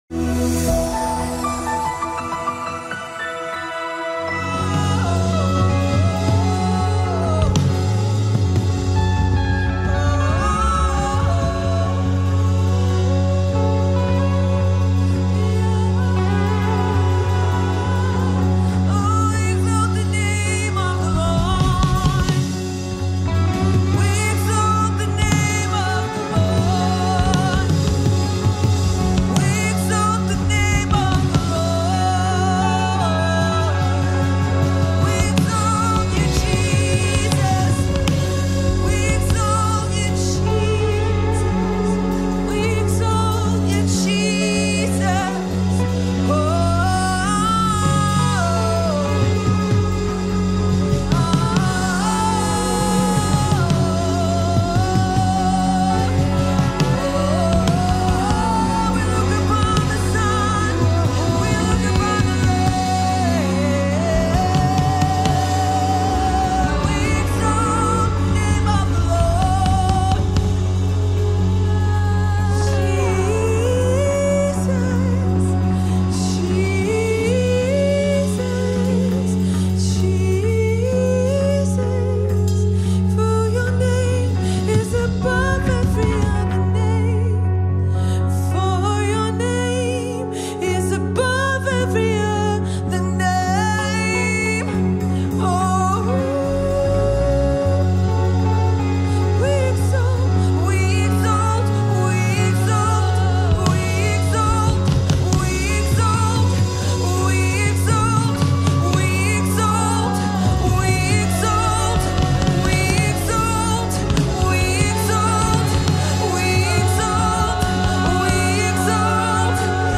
Категория : Молитвы Центра